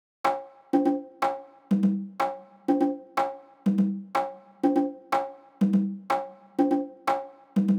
08 Congas.wav